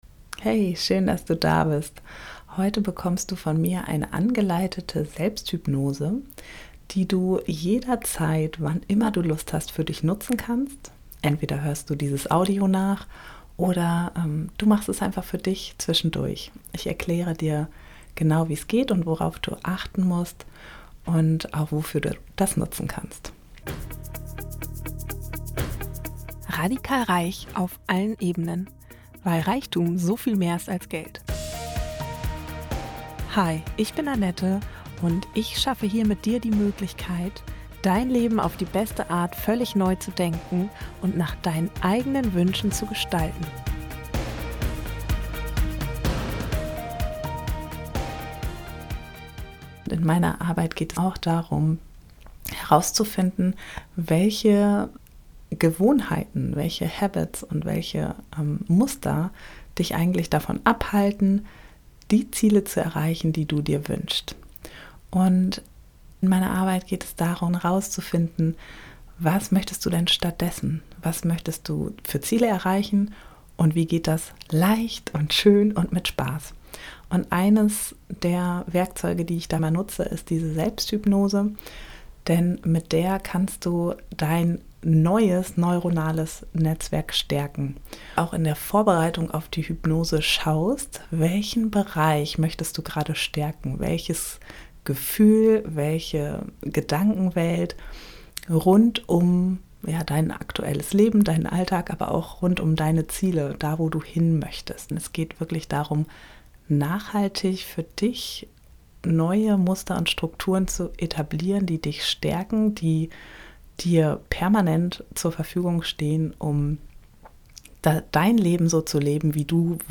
Die ist eine leichte Anleitung für eine effektive Selbst-HYPNOSE.